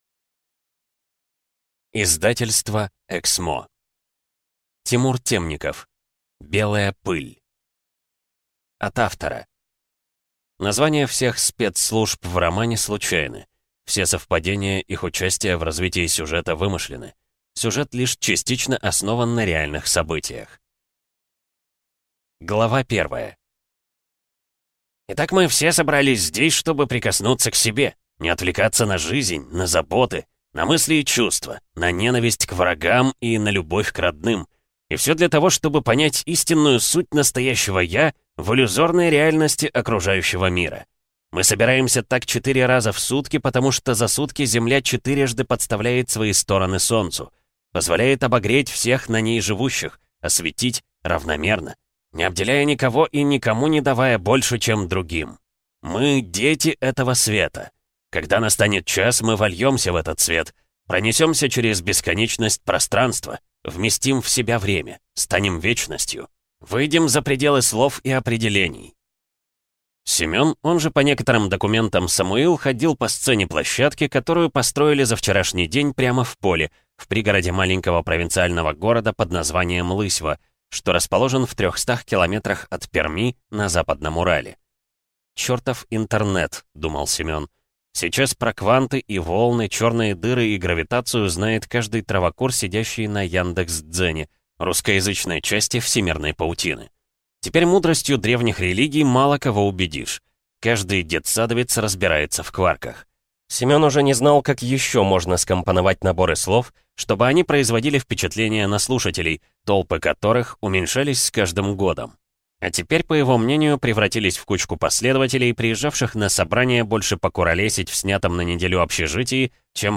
Аудиокнига Белая пыль из жанра Другие детективы - Скачать книгу, слушать онлайн